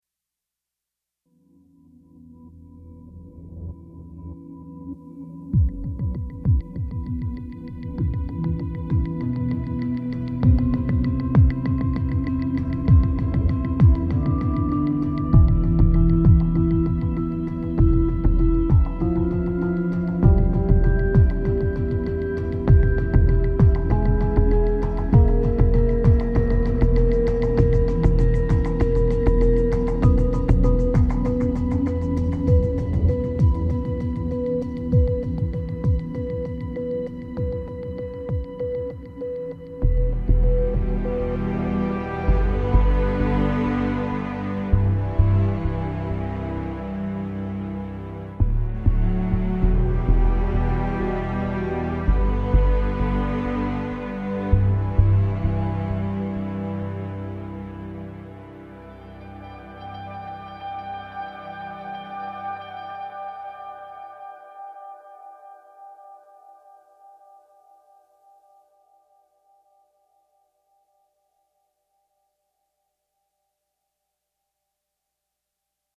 SUSPENSE/DARK